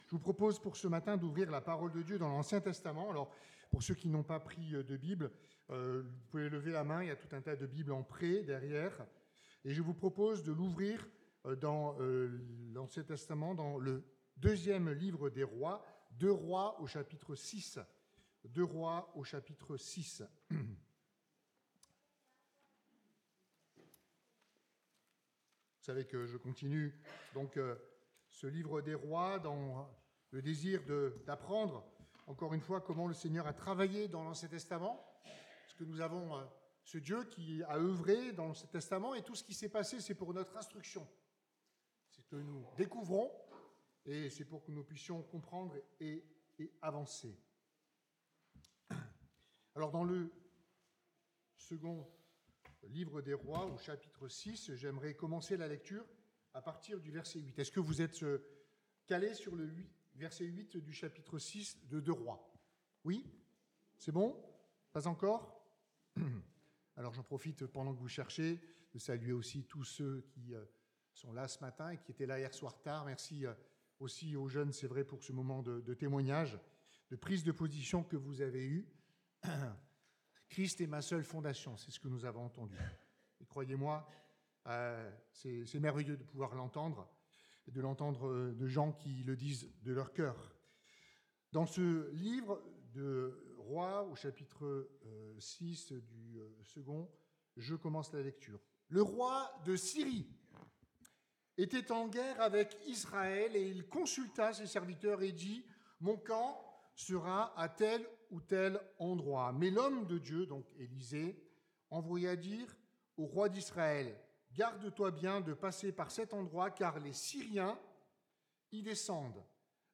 Culte du dimanche 12 Avril 26
Prédications